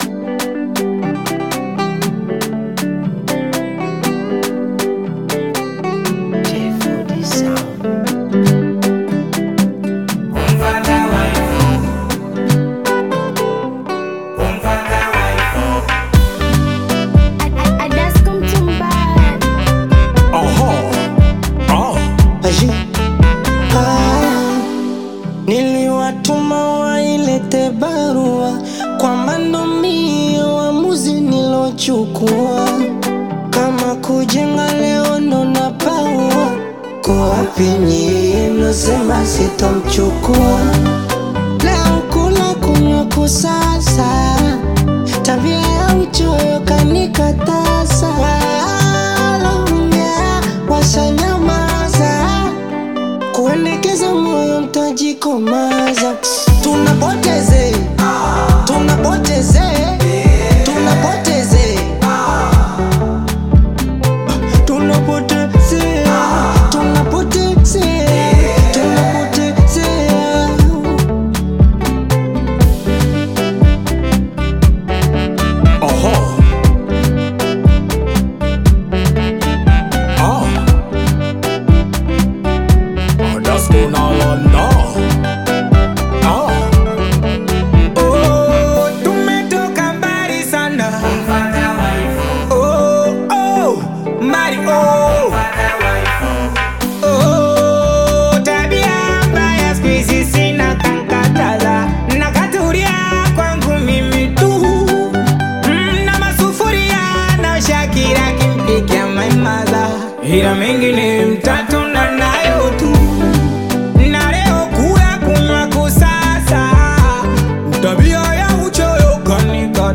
Singeli music track
Tanzanian Bongo Flava artists
Singeli song